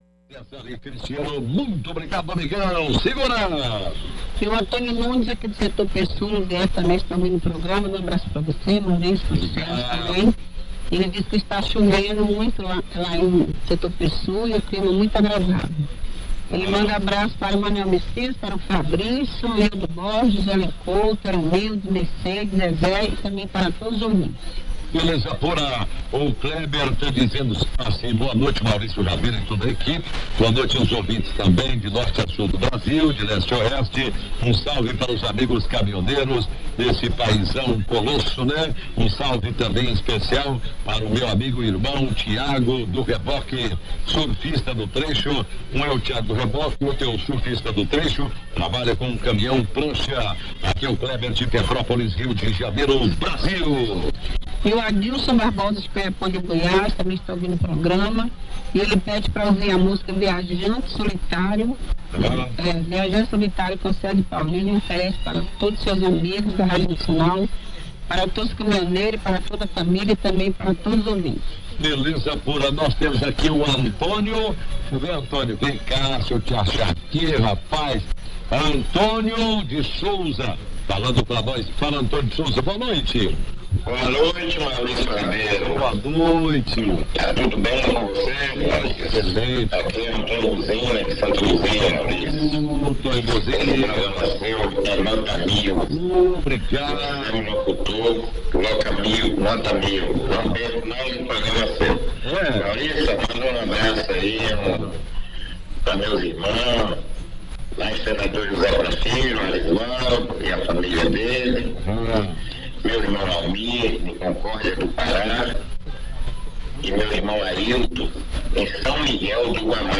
Good signal into EM80. 58+50, somewhat tinny sounding.
0050 UTC - Portuguese speaking OM, very dramatic at times, sports talk show?
0130 UTC - music